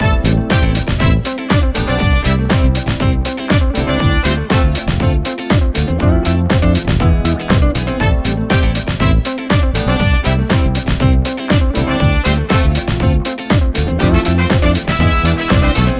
31  Full Mix.wav